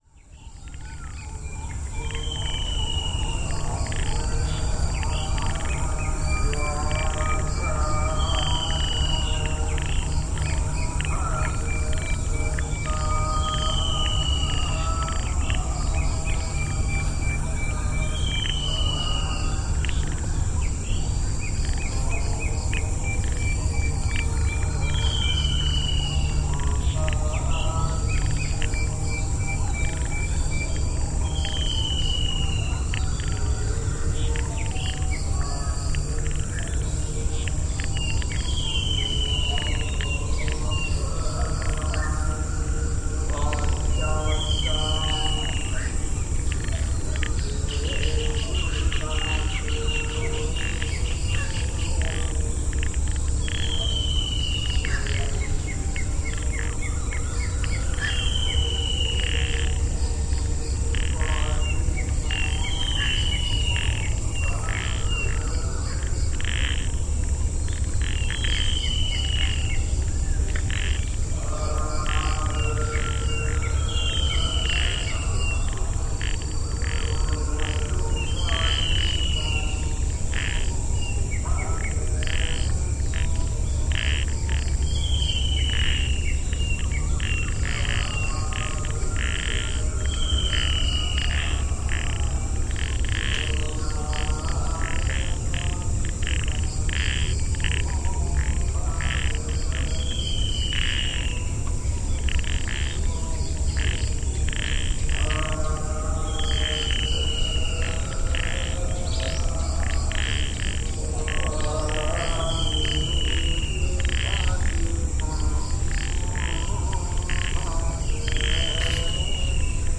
Mangrove swamp and temple prayers, Heenatigala